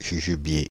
Ääntäminen
Ääntäminen Paris: IPA: [ʒu.ʒu.bje] Tuntematon aksentti: IPA: /ʒy.ʒy.bje/ Haettu sana löytyi näillä lähdekielillä: ranska Käännös Konteksti Substantiivit 1. jujube kasvitiede Suku: m .